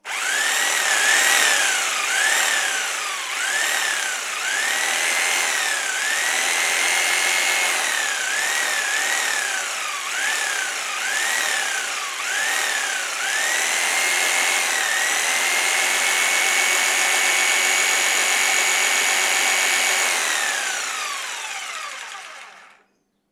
Título Sierra de calar Formateatu: audio/mpeg Fitxategiaren tamaina 364.59 KB Iraupena: 23 seconds: Me gusta Descriptores calar sierra Energía y su transformación Electricidad y electrónica.